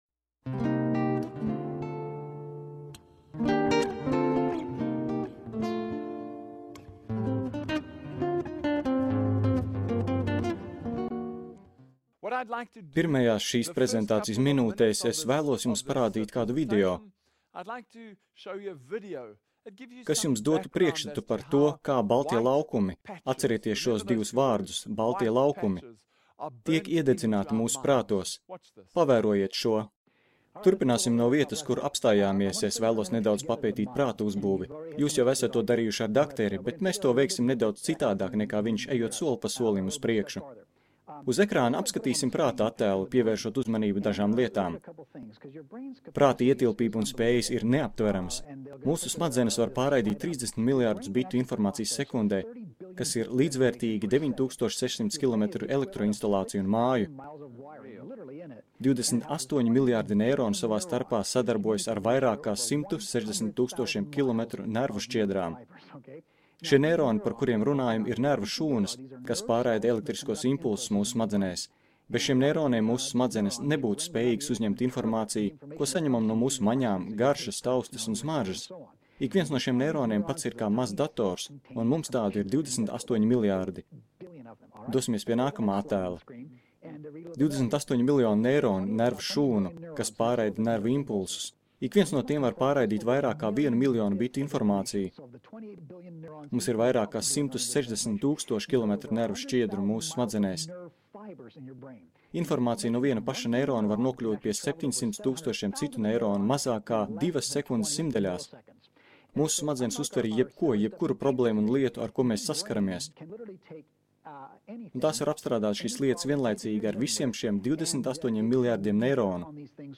LEKCIJA PAREDZĒTA TIKAI PIEAUGUŠO AUDITORIJAI